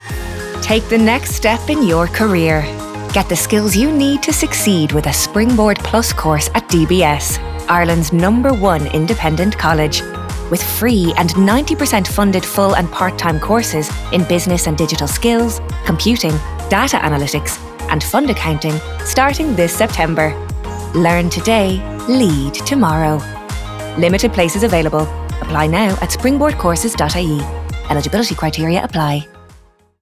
DBS-Radio-4-30-sec-Springboard.wav